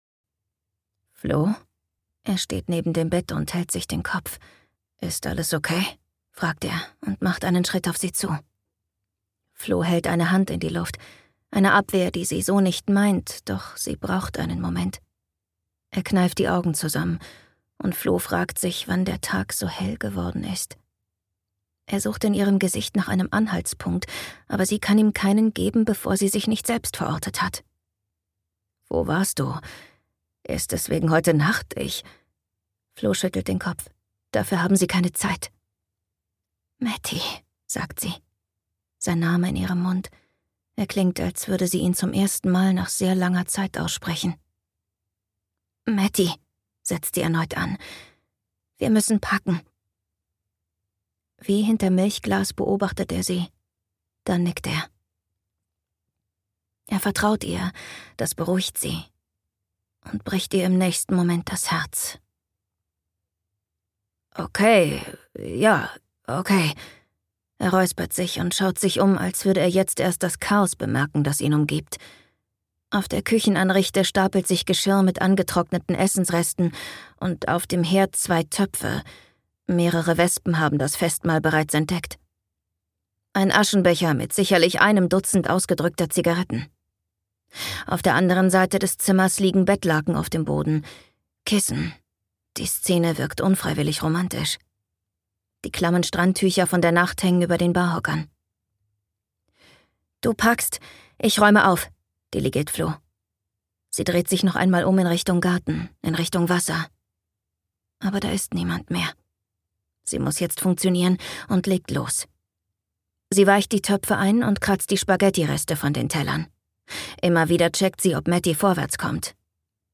Ein berührendes Hörbuch über weibliche Intuition und Geheimnisse, für die es keine Worte gibt!
Gekürzt Autorisierte, d.h. von Autor:innen und / oder Verlagen freigegebene, bearbeitete Fassung.